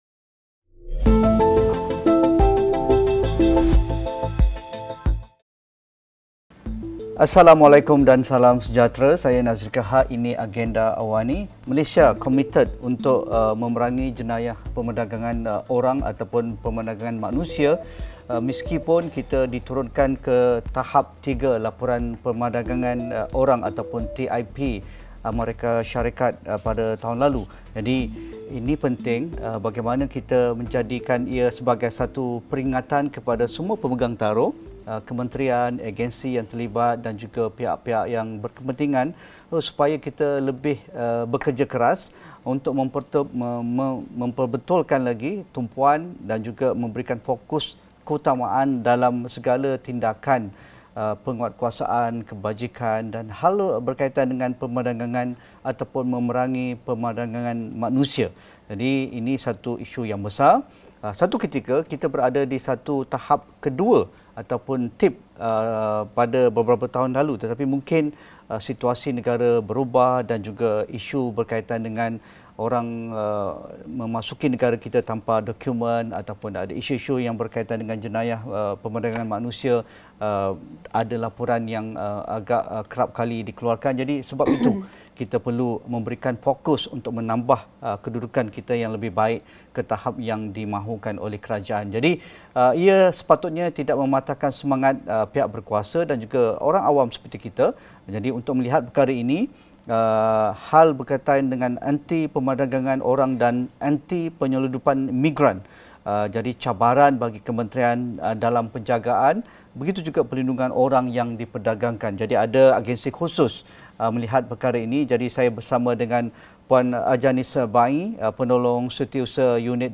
Pemerdagangan orang merupakan jenayah kompleks dan kebanyakannya melibatkan sindiket yang beroperasi secara tersusun dalam rangkaian yang luas. Sejauh mana cabaran pihak yang terlibat dalam pengurusan orang yang diperdagangkan ini? Diskusi 9 malam